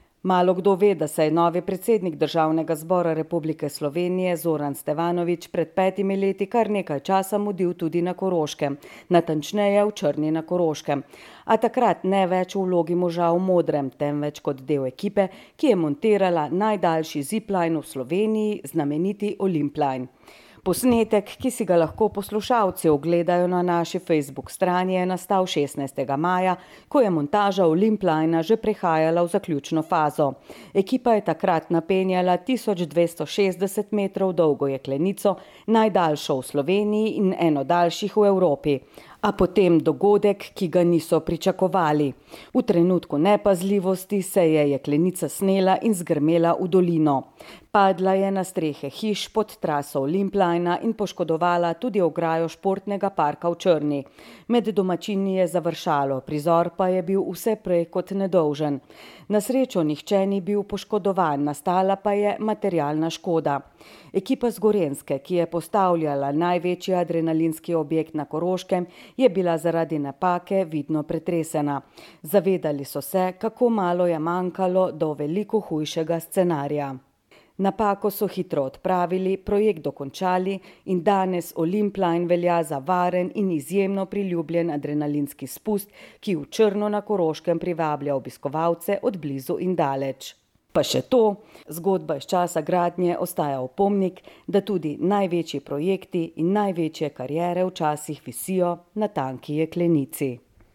Pred šestimi leti ga je na delovnem mestu pri montaži Olimplina v Črni na Koroškem